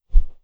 Close Combat Swing Sound 24.wav